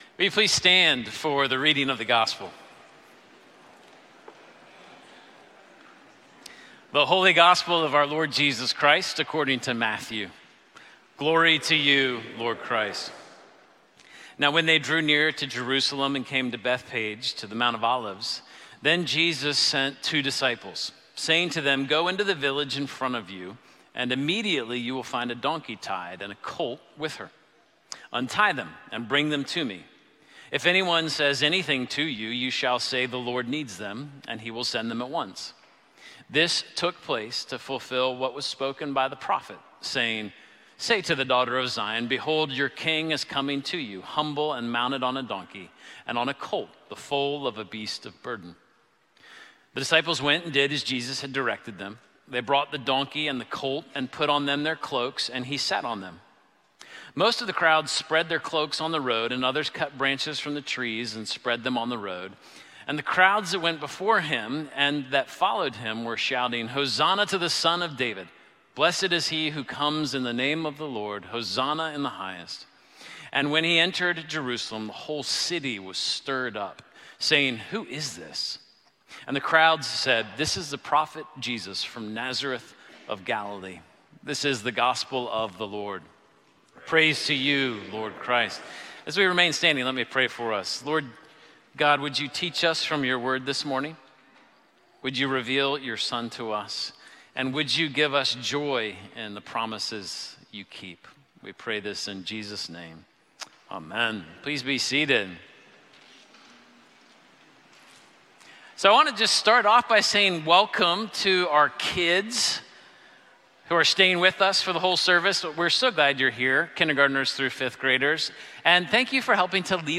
Sermons - Holy Trinity Anglican Church